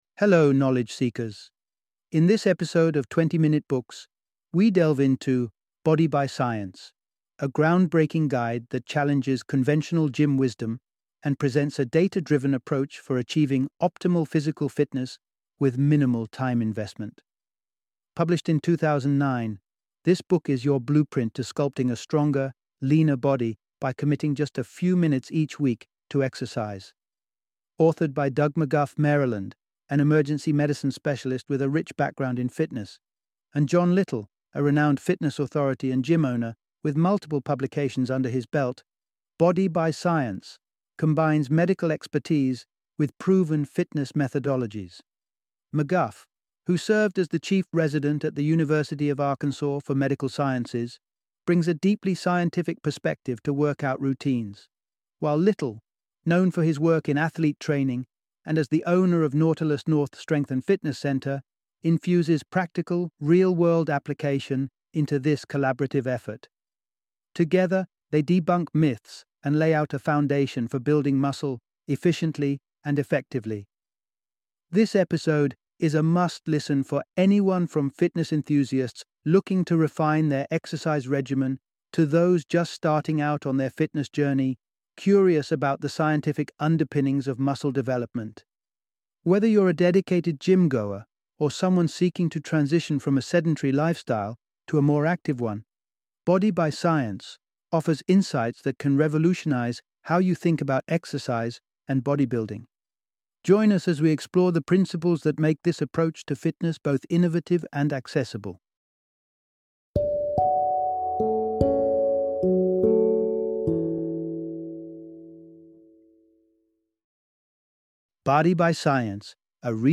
Body by Science - Audiobook Summary